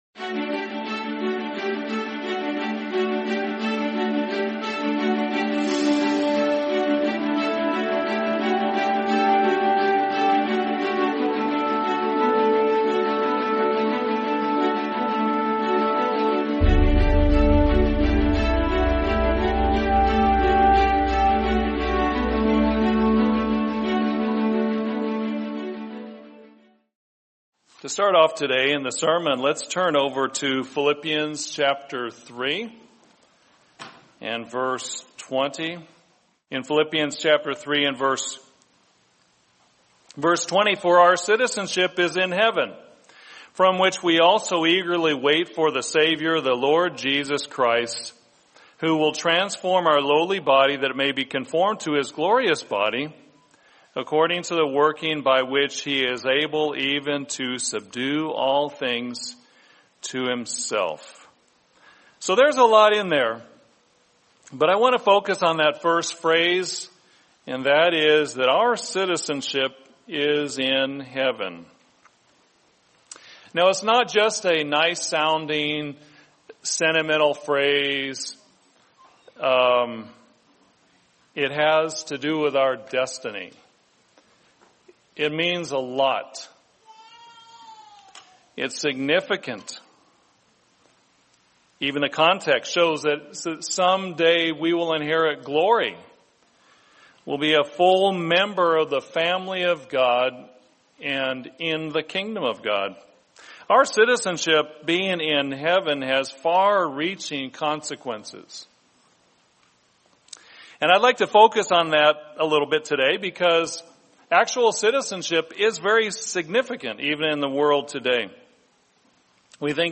Because Our Citizenship Is in Heaven | Sermon | LCG Members